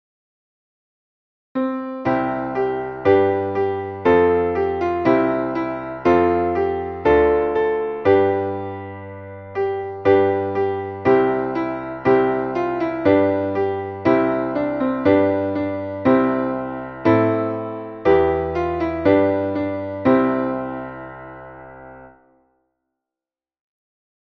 Traditionelles Weihnachts-/ Kirchenlied